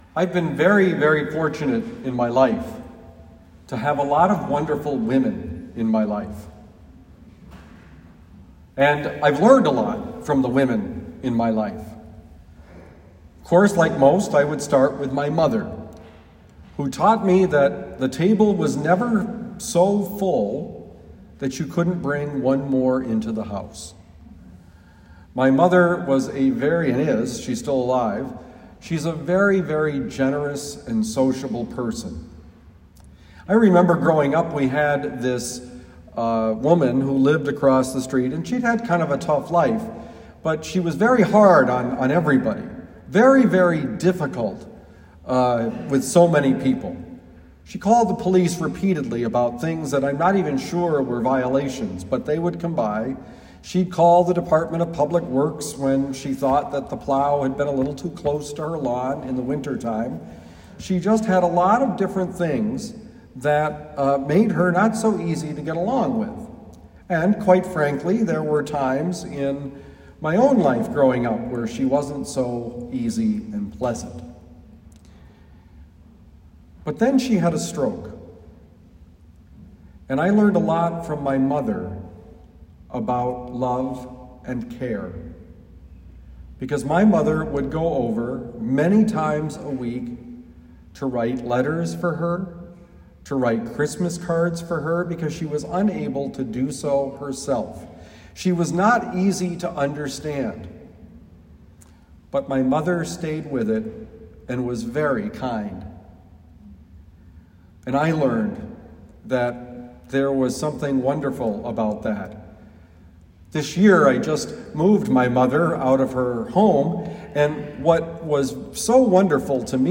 Homily for Sunday, November 7, 2021